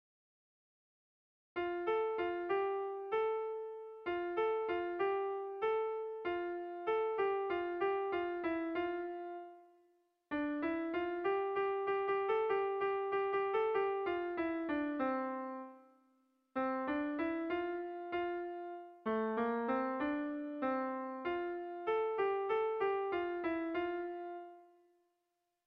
Kontakizunezkoa
Eibar < Debabarrena < Gipuzkoa < Basque Country